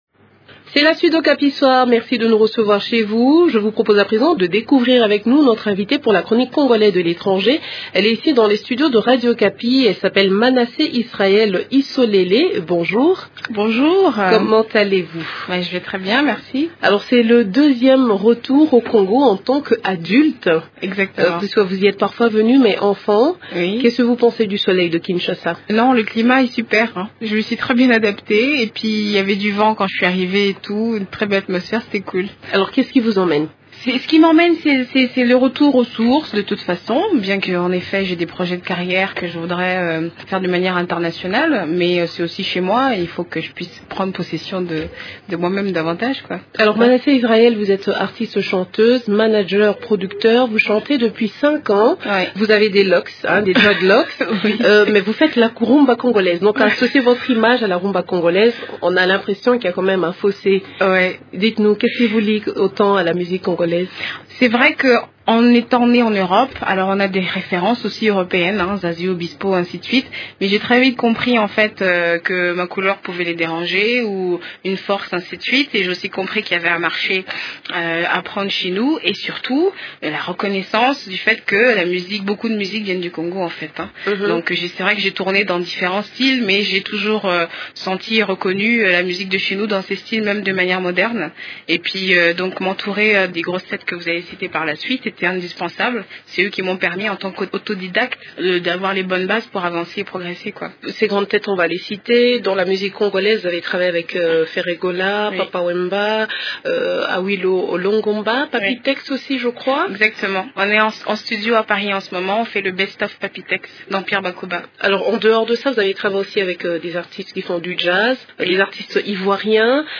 Dans un entretien avec